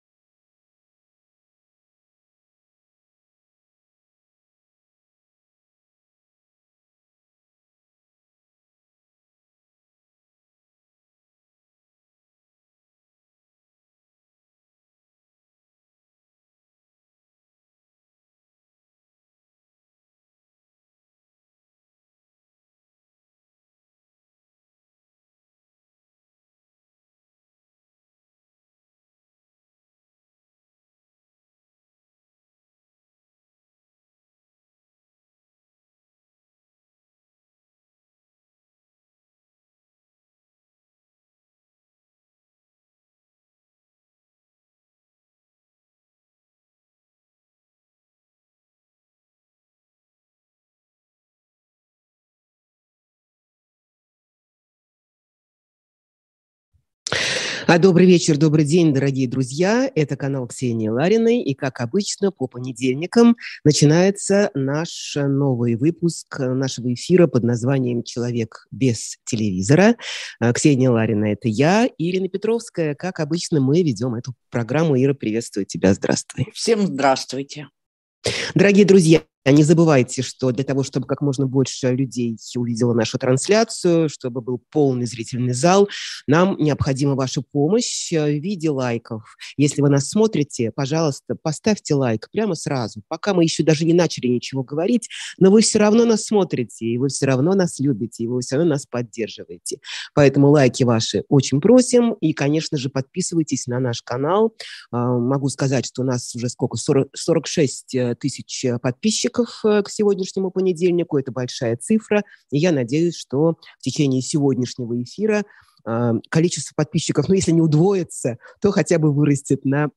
Эфир Ксении Лариной и Ирины Петровской